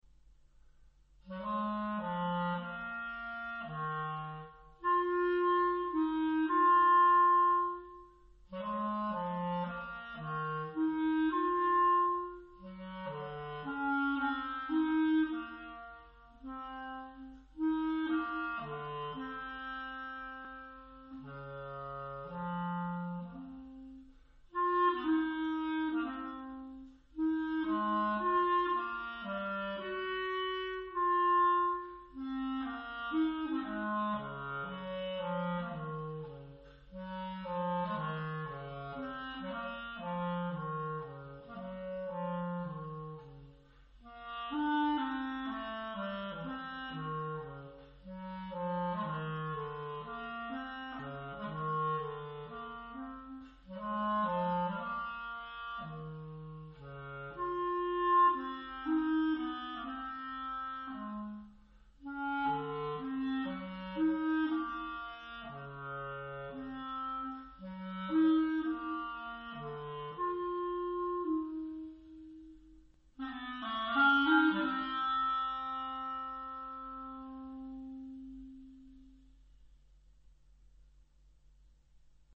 Clarinette en sib full Boehm (collection personnelle)
Pièce pour clarinette seule